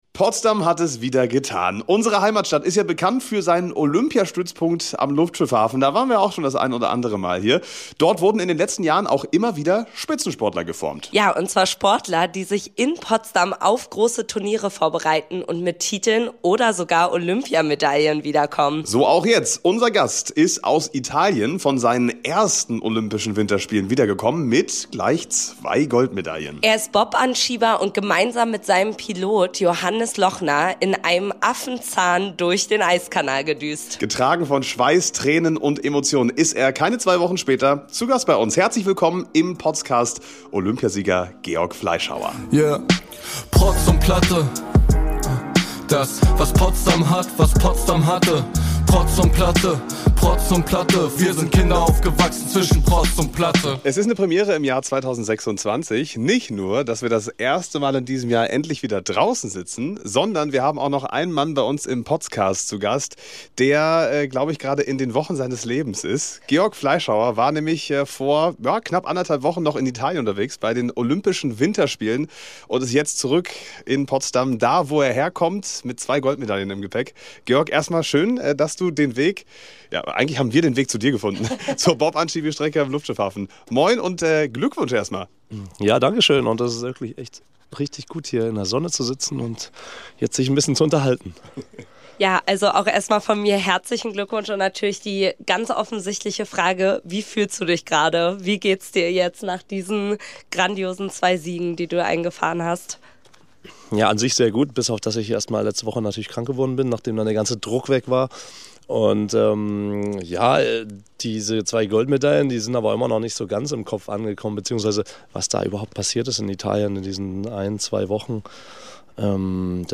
Georg Fleischhauer hat gleich zwei mal abgeräumt: Einmal im Zweier- einmal im Viererbob. Nicht mal zwei Wochen nach dem größten Erfolg seiner Karriere war er zu Gast im Potscast: Wir haben mit ihm über seine Medaillen und seinen Weg dorthin gesprochen. Und Bob sind wir mit Georg auch gefahren!